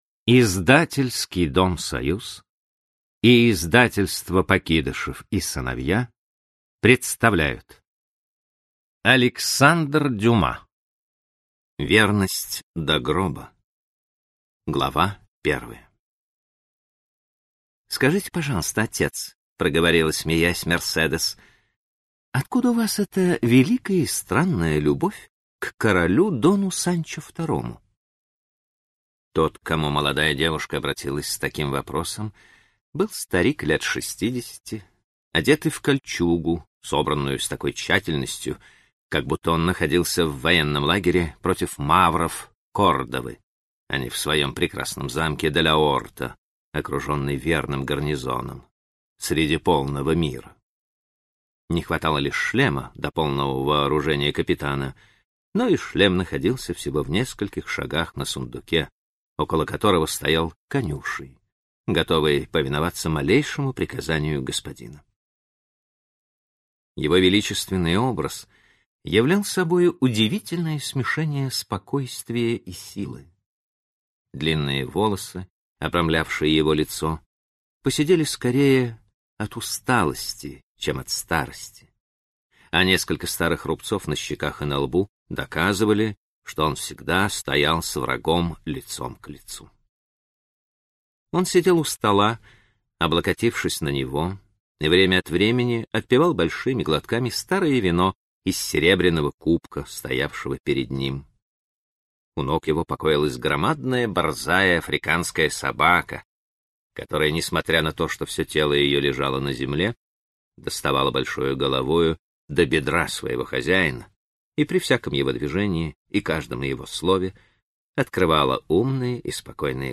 Аудиокнига Верность до гроба | Библиотека аудиокниг